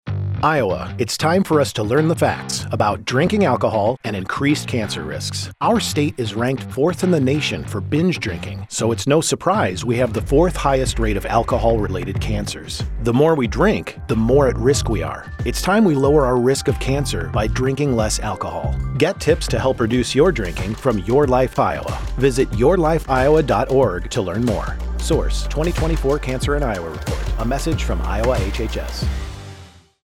:30 Radio Spot | Cancer & Alcohol | Straight Facts | Male